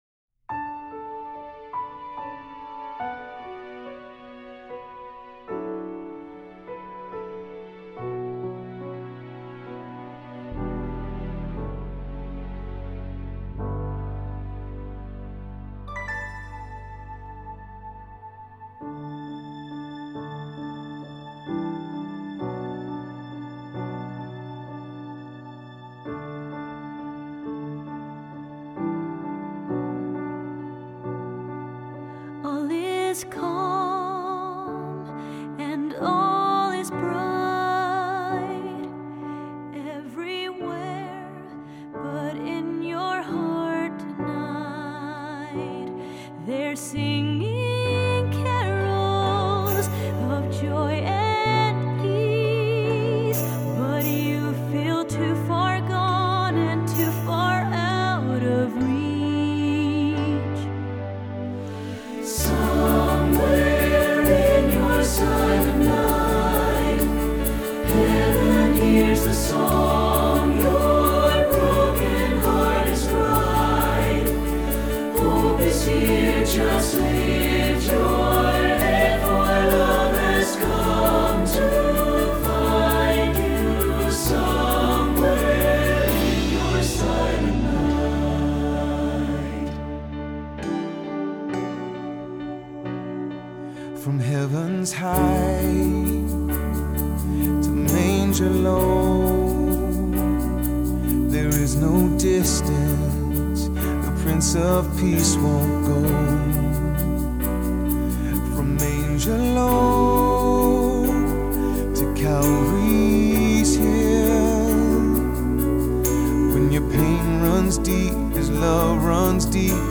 Choral Christmas/Hanukkah
SATB